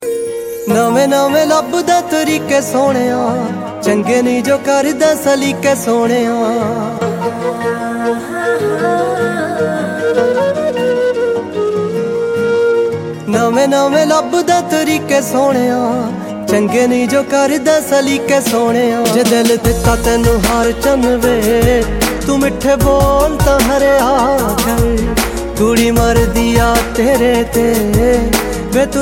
Feel the beats of Punjab